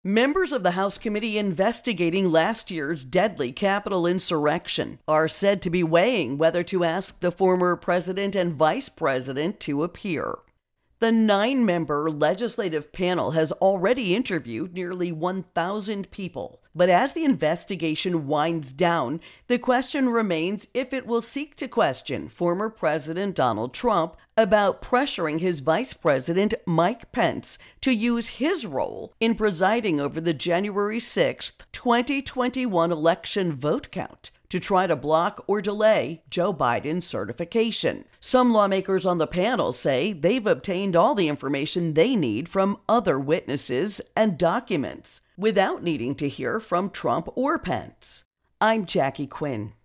Capitol Riot Investigation Witnesses Intro and Voicer